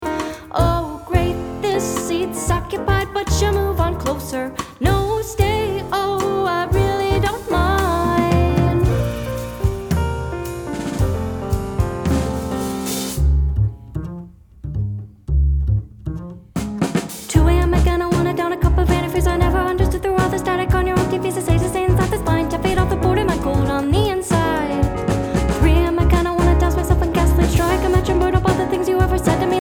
Vocalist | Songwriter